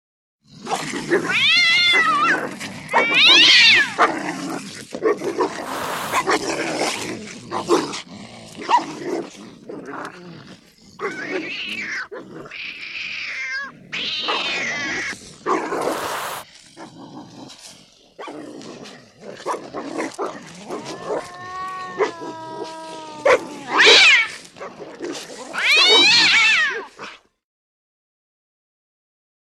На этой странице собраны звуки драки между кошкой и собакой – от яростного шипения и лая до забавного визга и рычания.
Шум схватки кошки и собаки